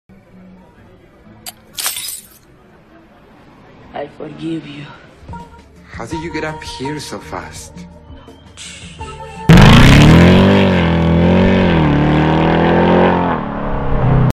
One sec it's tranquilo, the next your QTEC is bringing the ruido!